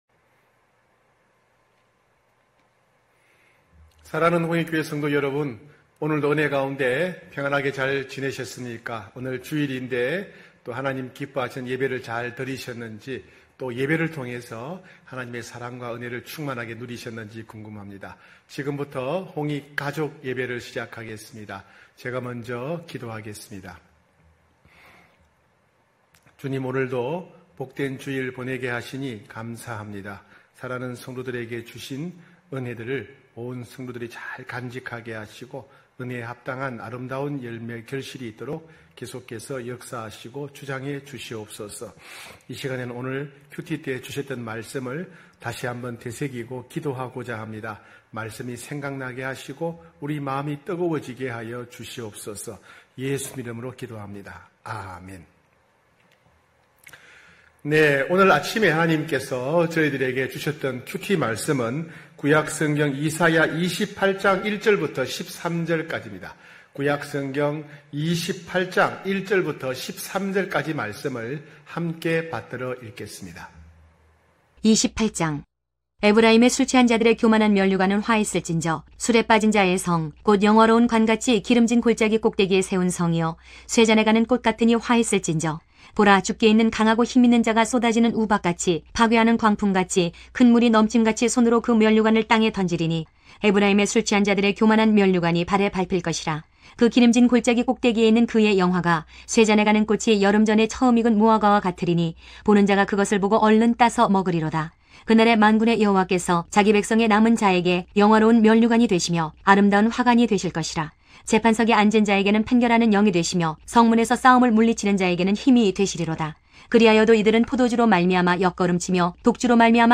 9시홍익가족예배(8월16일).mp3